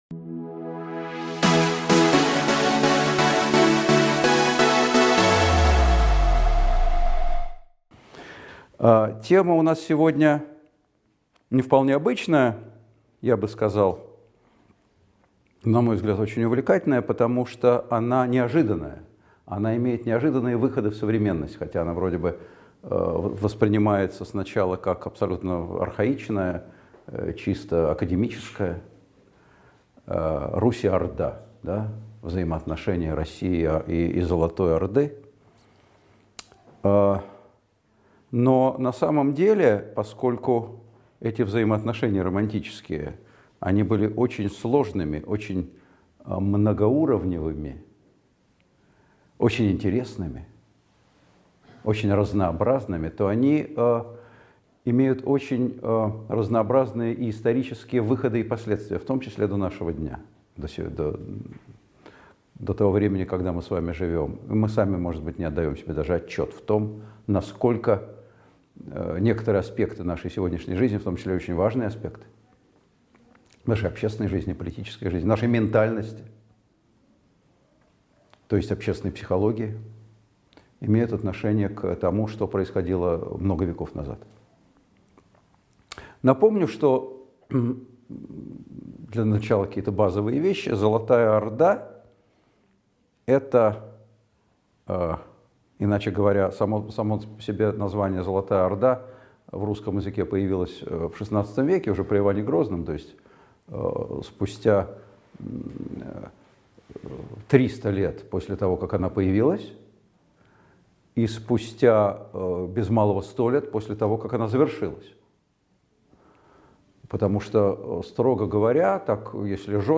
Аудиокнига Русь и Орда: враги или родня | Библиотека аудиокниг
Aудиокнига Русь и Орда: враги или родня Автор Николай Сванидзе Читает аудиокнигу Николай Сванидзе.